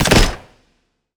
sci-fi_weapon_rifle_bolt_shot_01.wav